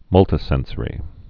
(mŭltĭ-sĕnsə-rē)